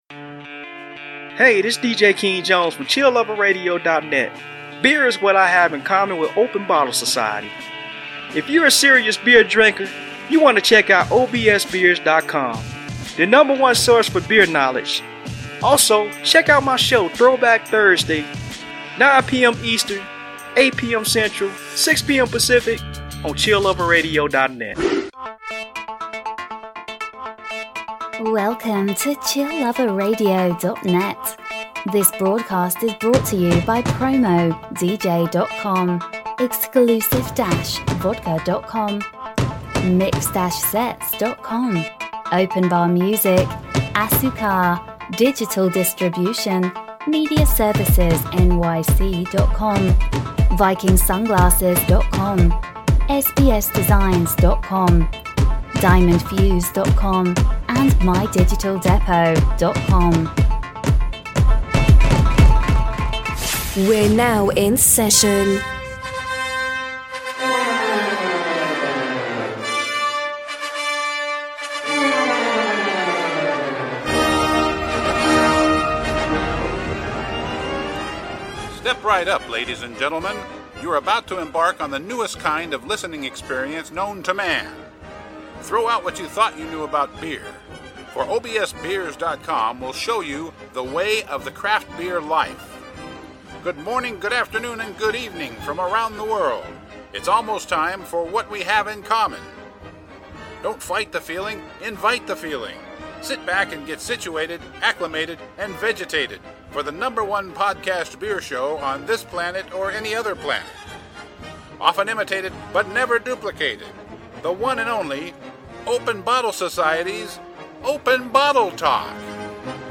Styles: Beer Talk, Beer News, Beer, Craft Beers, Talk Show, Comedy